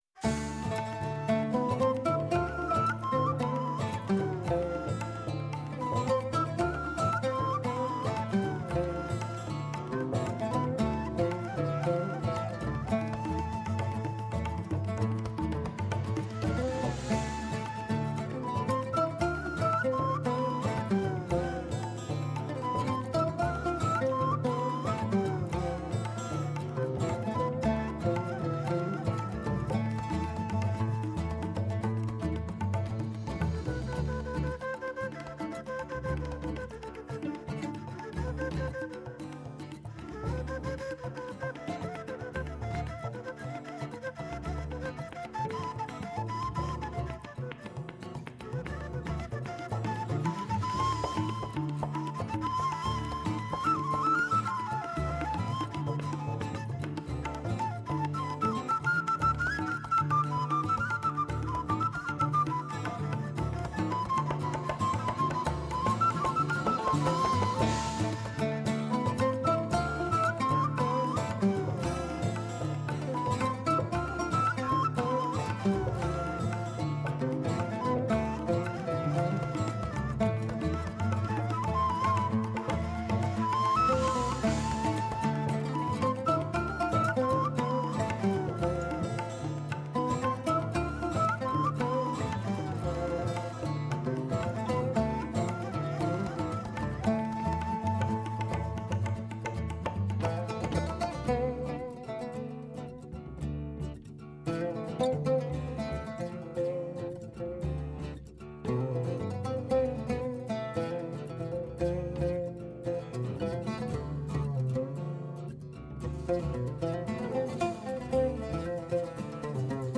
ως καλεσμένοι στο στούντιο
Συνομιλήσαμε, επίσης, τηλεφωνικά